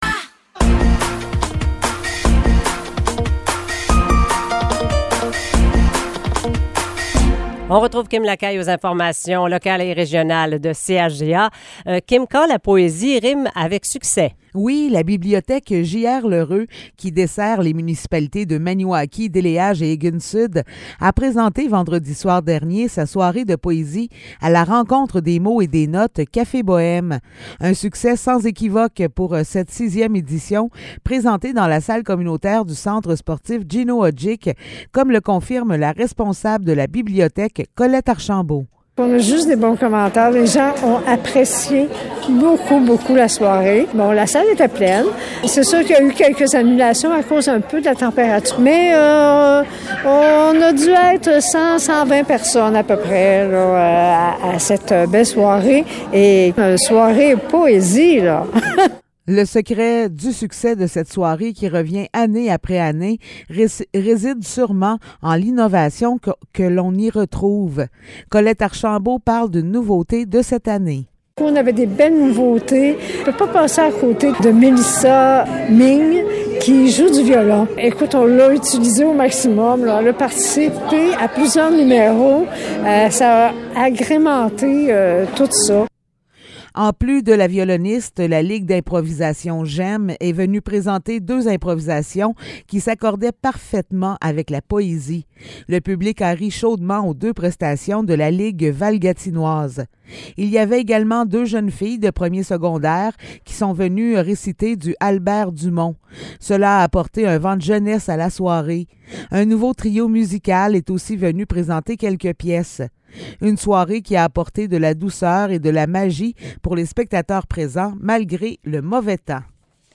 Nouvelles locales - 3 avril 2023 - 7 h